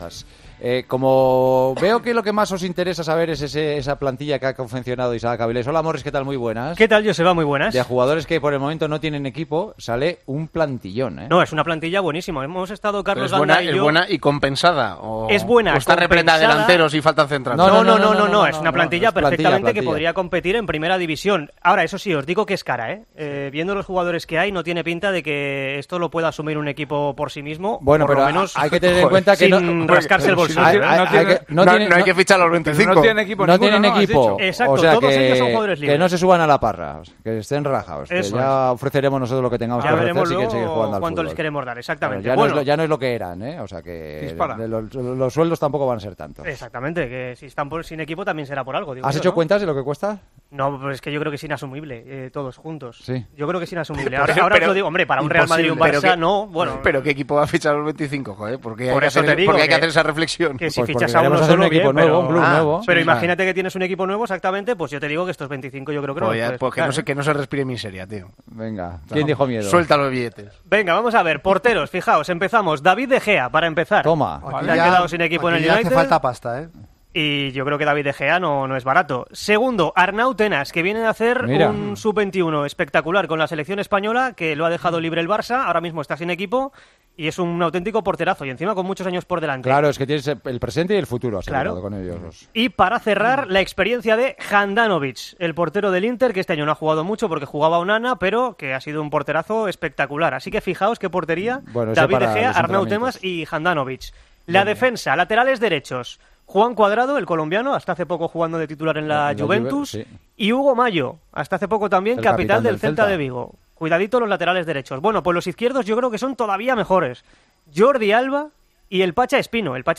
El once de futbolistas libres que los tertulianos de El Partidazo de COPE eligen